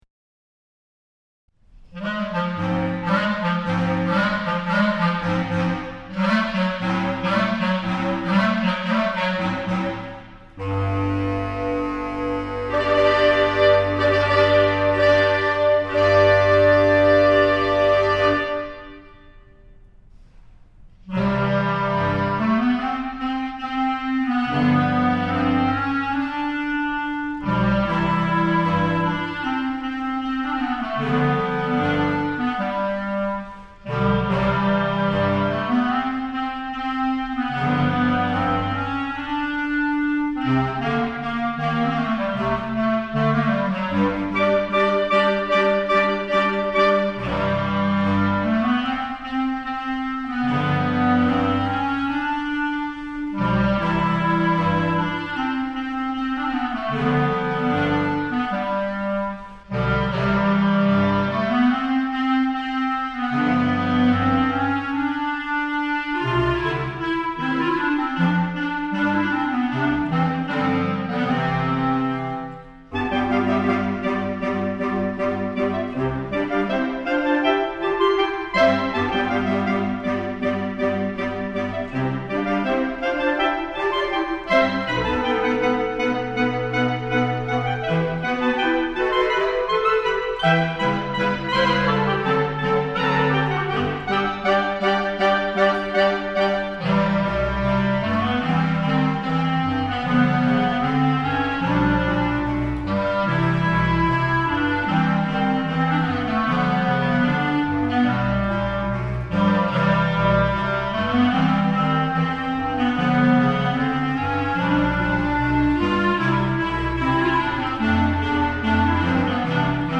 クラリネット７重奏版
C Major
（原曲から長２度下げ）